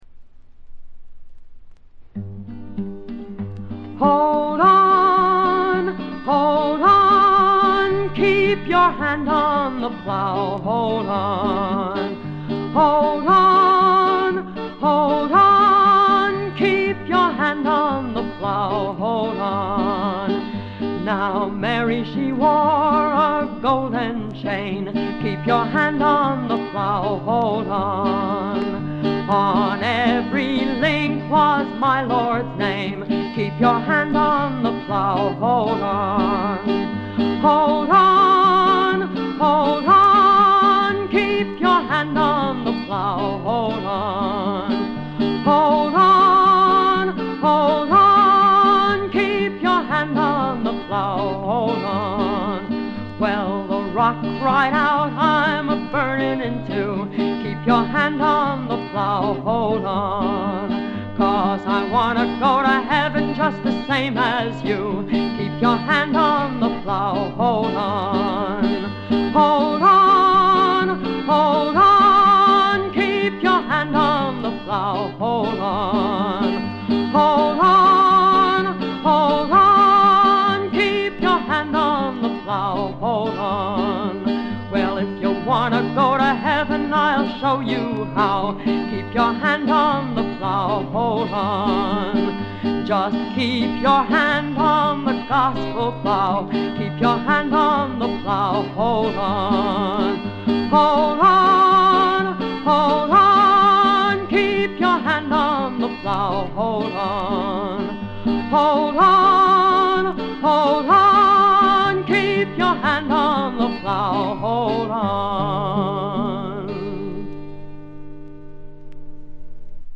軽微なバックグラウンドノイズにチリプチ少し。
魅力的なヴォイスでしっとりと情感豊かに歌います。
試聴曲は現品からの取り込み音源です。
Recorded At - WDUQ, Pittsburgh, PA